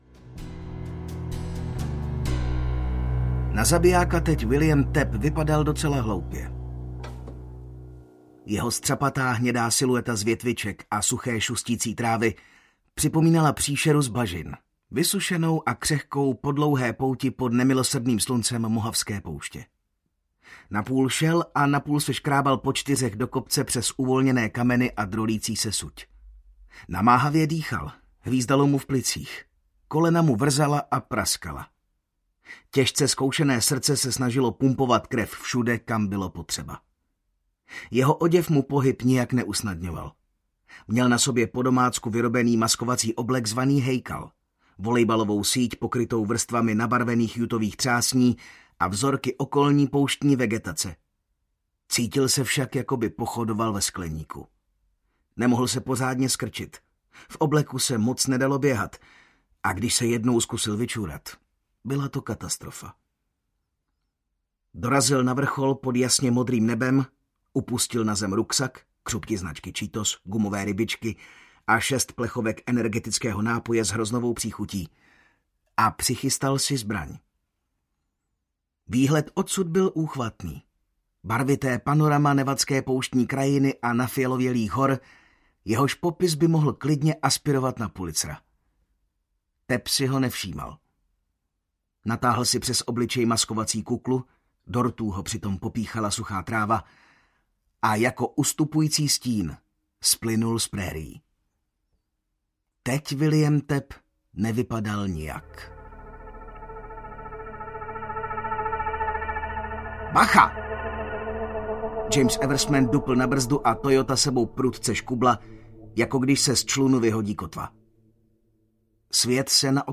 Na dostřel audiokniha
Ukázka z knihy